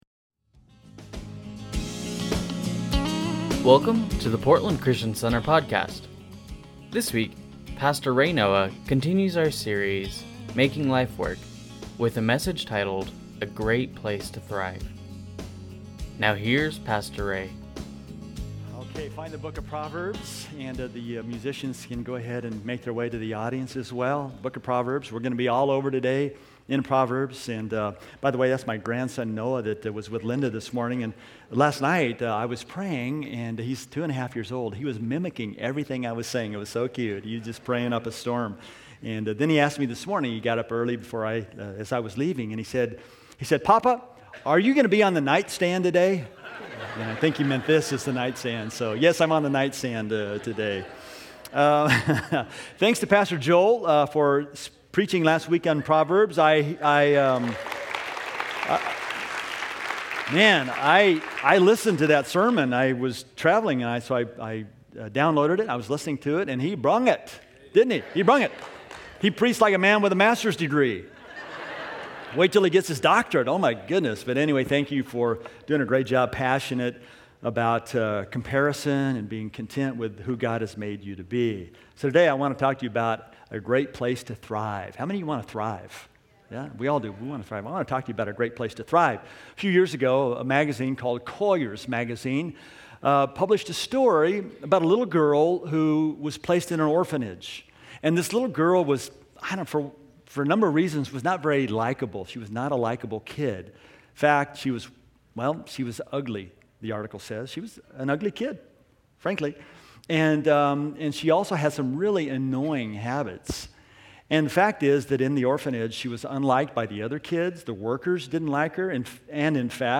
Sunday Messages from Portland Christian Center A Great Place To Thrive May 22 2016 | 00:37:24 1x 00:00 / 00:37:24 Subscribe Share Spotify RSS Feed Share Link Embed